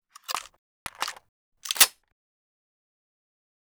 lmg_reload.wav